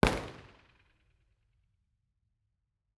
IR_EigenmikeHHL2_processed.wav